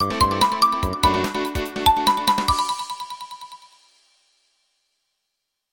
PowerOff (Alt).ogg